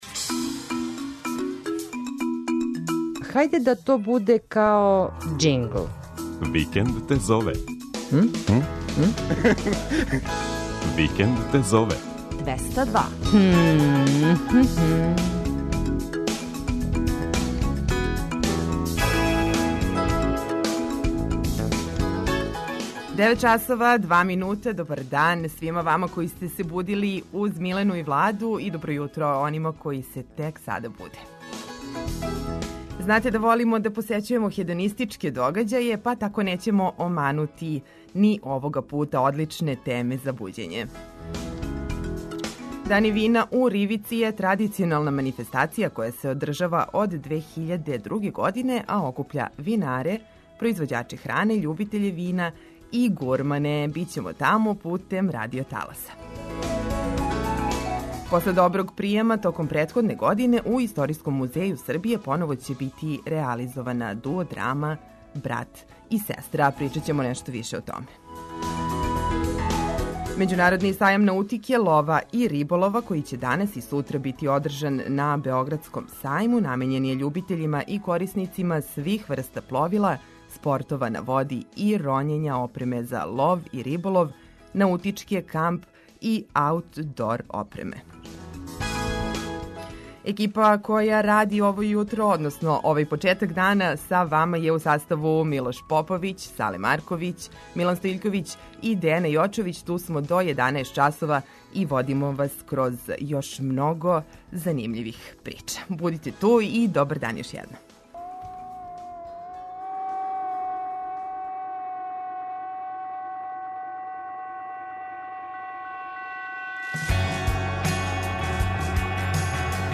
Бићемо тамо путем радио таласа.